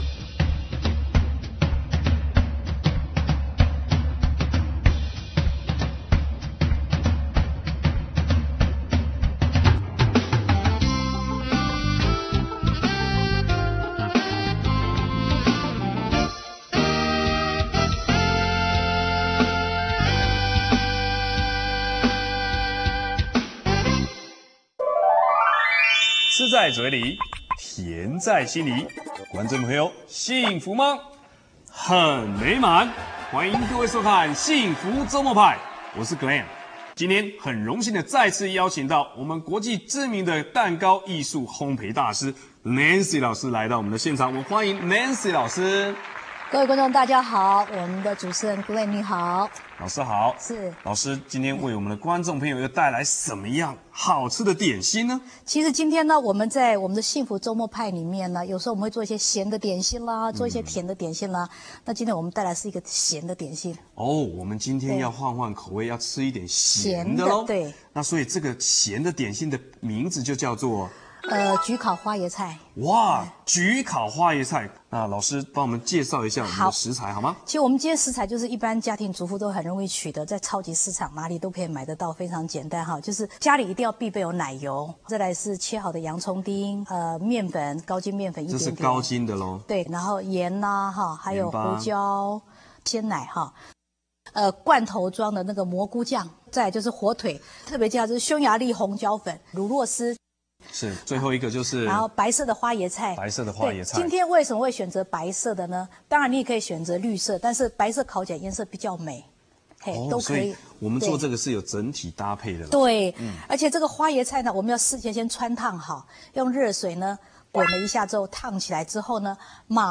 [餅乾] 幸福週末派--焗烤花椰菜 / 橘子小西餅(電視教學) - 看板baking